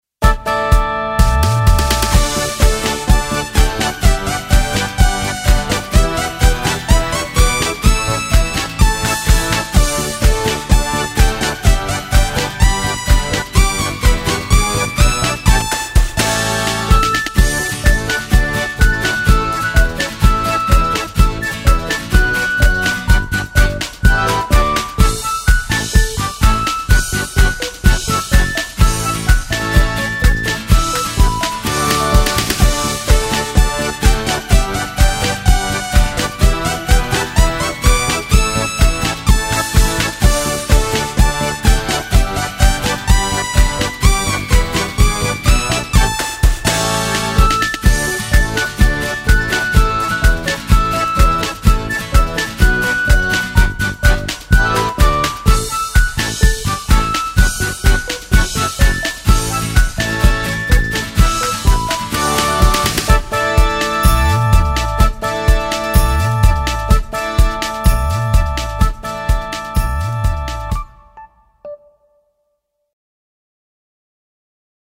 羊咩咩(純伴奏版) | 新北市客家文化典藏資料庫